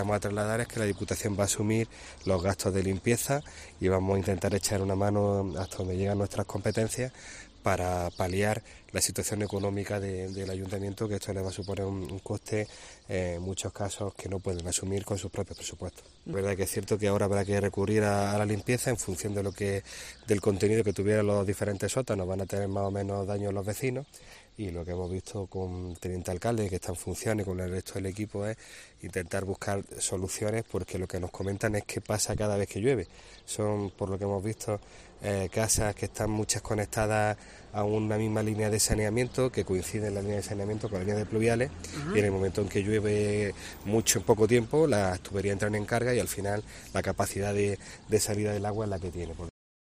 Francis Rodríguez, Presidente de la Diputación